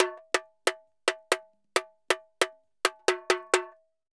Tamborin Merengue 136-1.wav